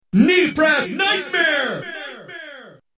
The announcer will also say the name of the move depending on the region: